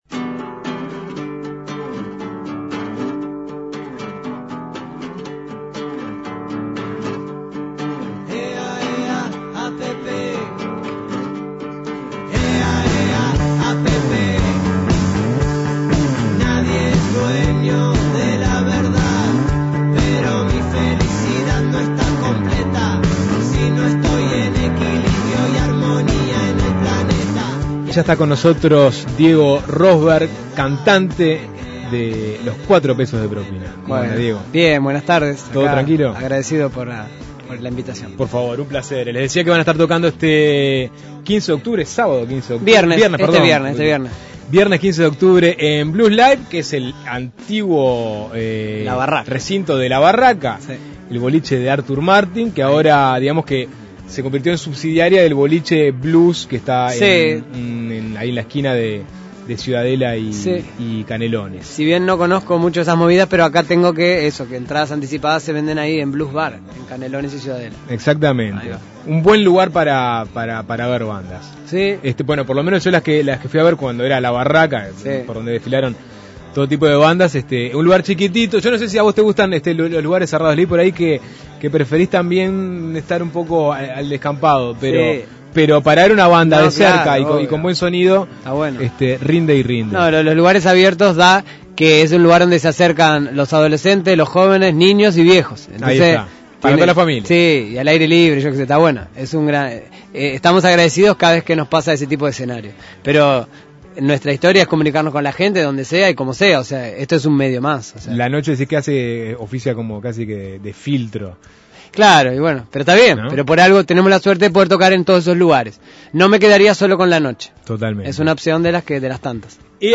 Entrevistas Cuatro Pesos de Propina presenta "Juan" Imprimir A- A A+ Cuatro Pesos de Propina presenta Juan, su segundo disco de estudio, el viernes en Bluzz Live.